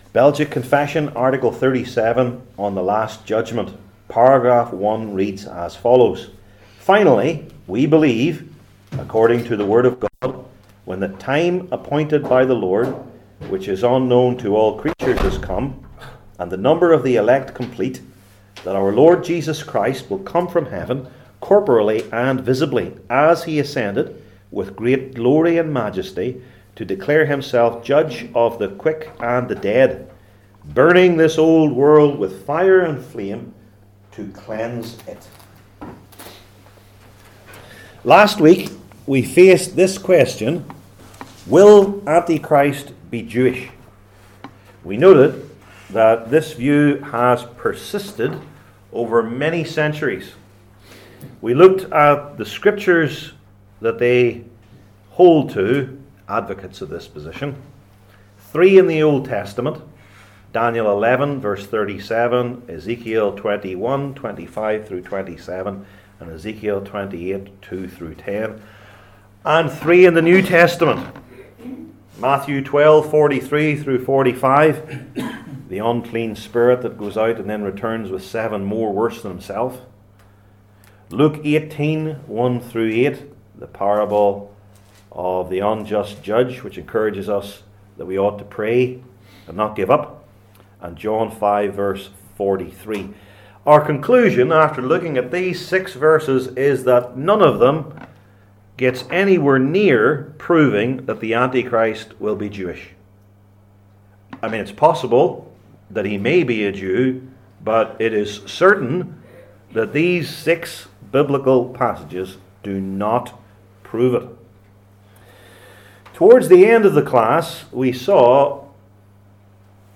Series: Belgic Confession 37 , The Last Judgment Passage: Genesis 49:1-18 Service Type: Belgic Confession Classes